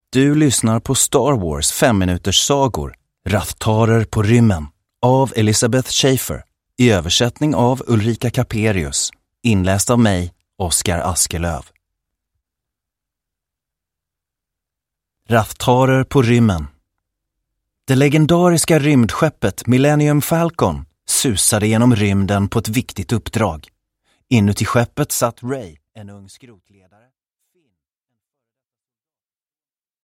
Rathtarer på rymmen! Den elfte berättelsen ur Star Wars 5-minuterssagor – Ljudbok – Laddas ner